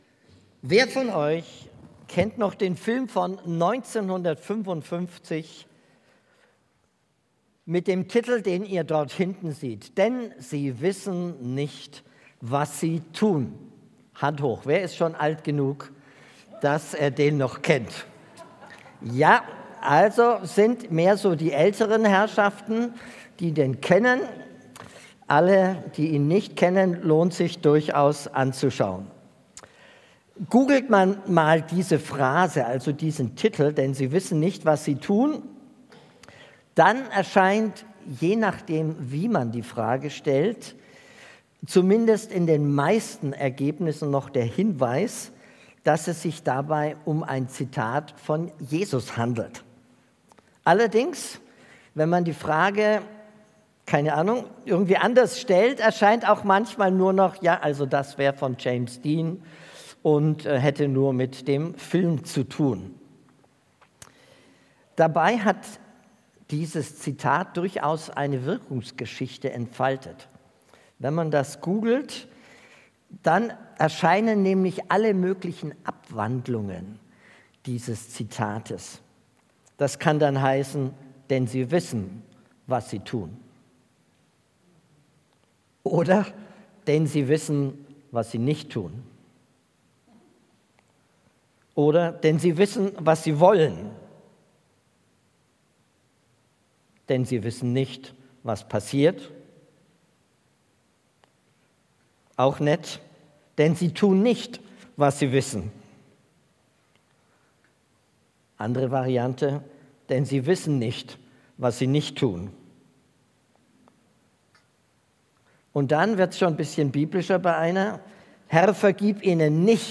Predigt Thema